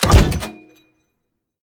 tank-door-close-1.ogg